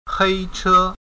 hēichē   нелегальное такси, «черное» такси